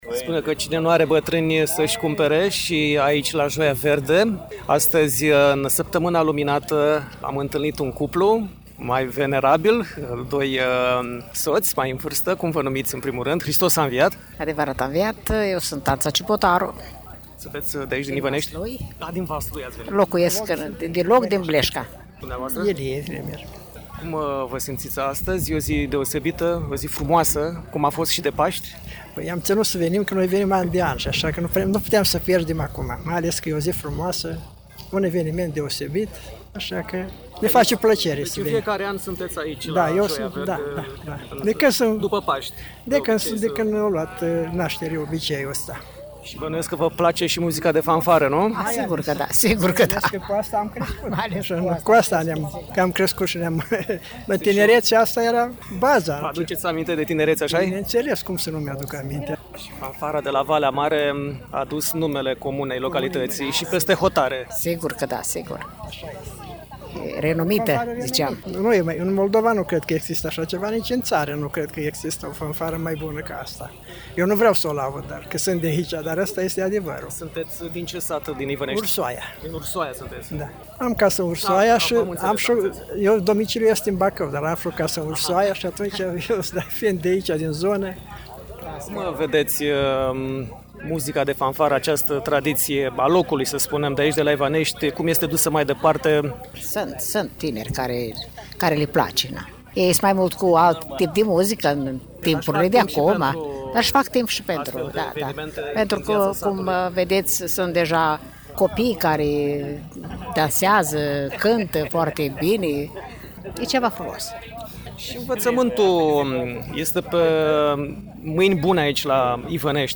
”Joia Verde” la Ivănești, Vaslui. Radioreportaj